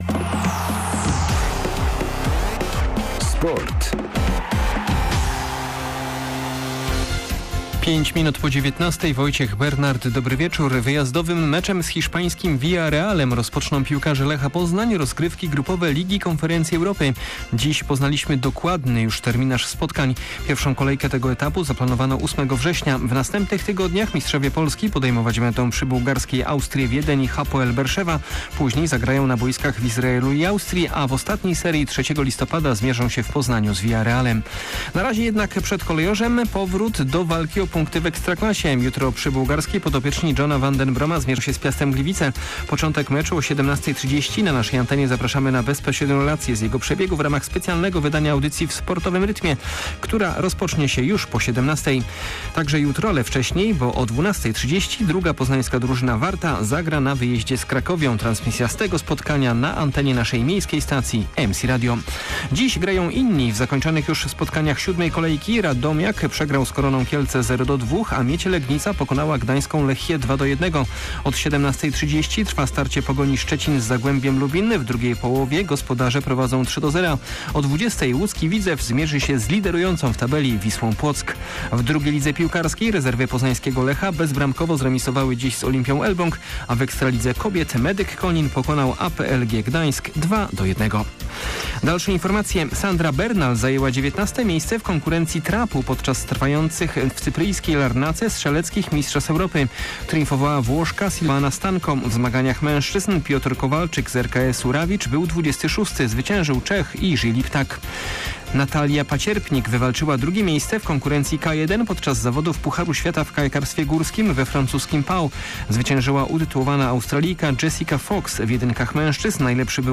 27.08.2022 SERWIS SPORTOWY GODZ. 19:05